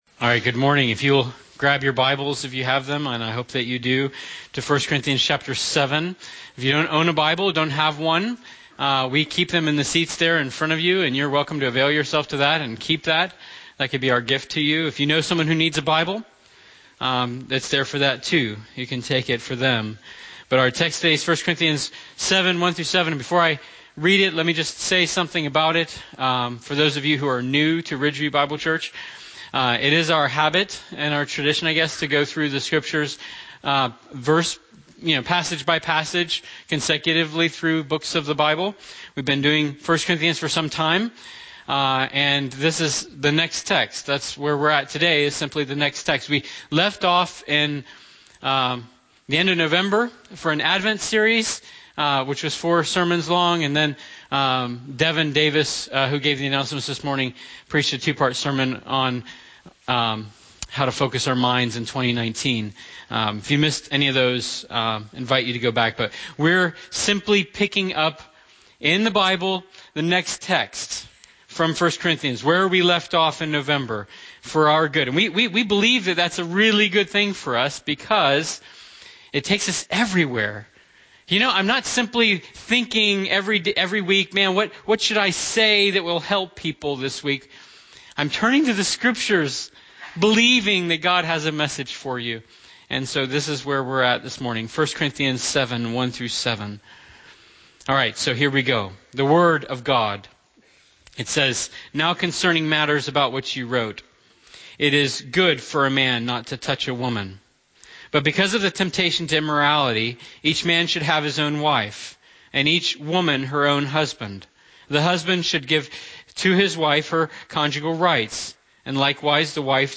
Sermons | Ridgeview Bible Church